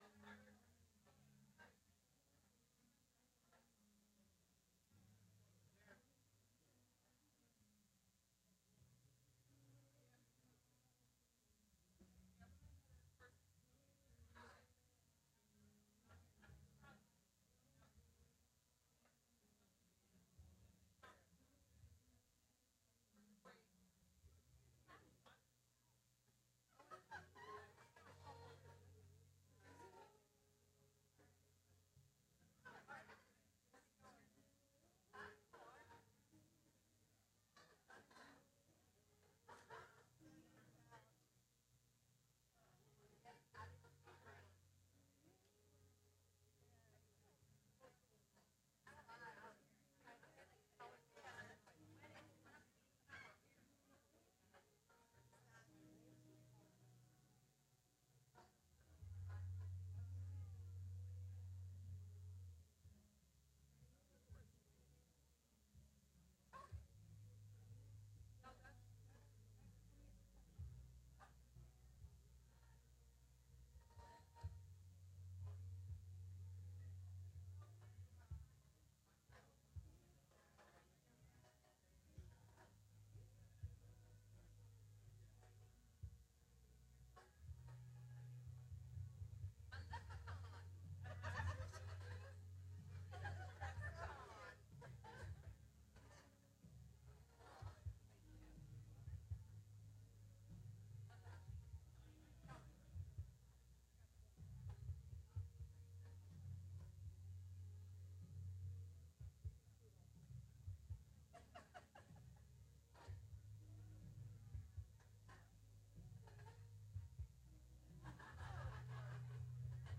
Service Audio Track Please note that most of our audio tracks will have several minutes (usually 2-3) of silence at the beginning of the track because our services start with a video only informational loop.